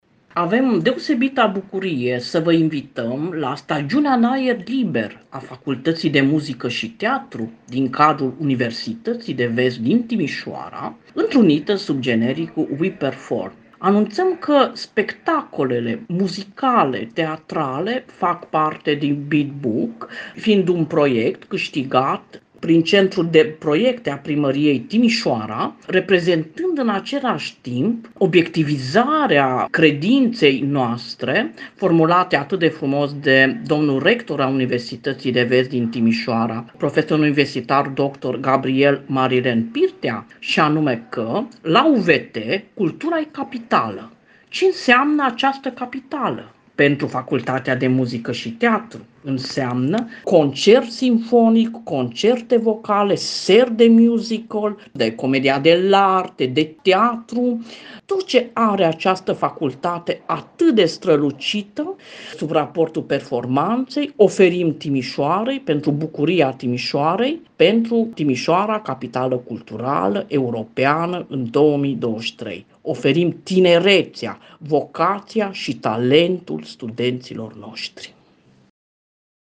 interviu Radio Timişoara, în exclusivitate